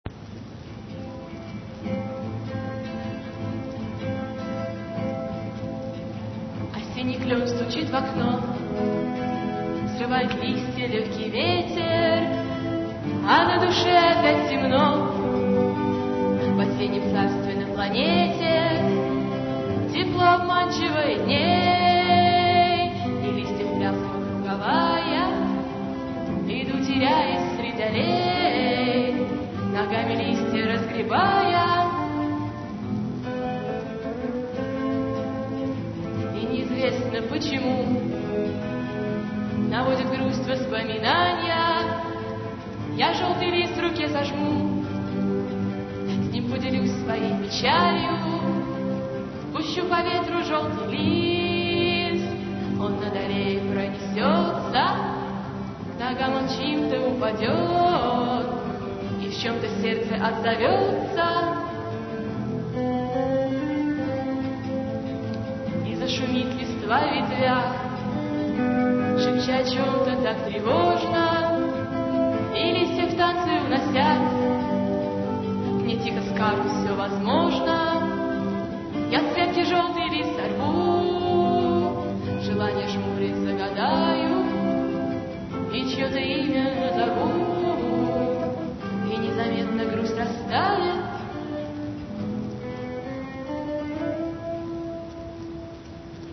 Бардовский концерт
декабрь 2000, Гимназия №45
282 kb, авторское исполнение